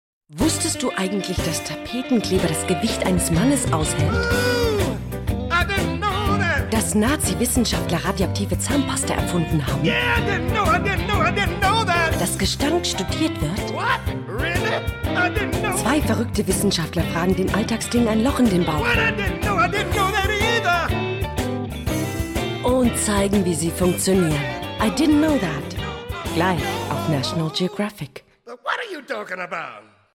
deutsche Sprecherin mit warmer, sinnlicher, sehr wandlungsfähiger Stimme, Stimmlage mittel / tief,
Sprechprobe: eLearning (Muttersprache):